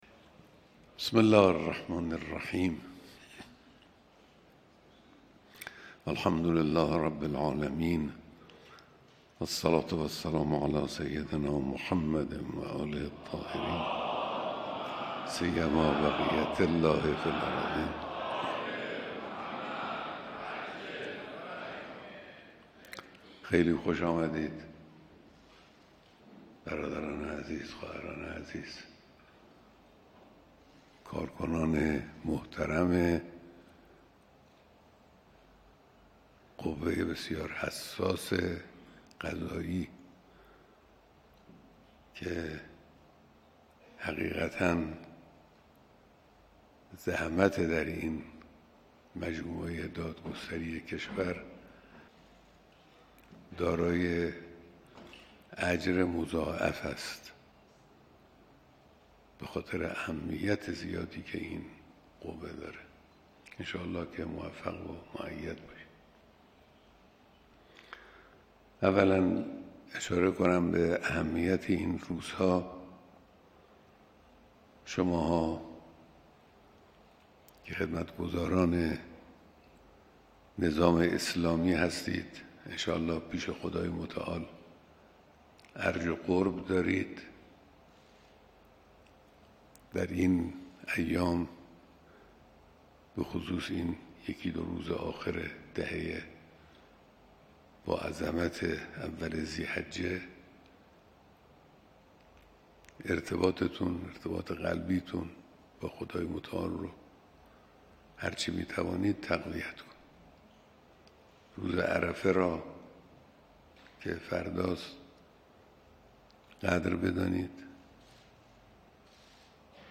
بیانات در دیدار رئیس، مسئولان و جمعی از کارکنان قوه قضائیه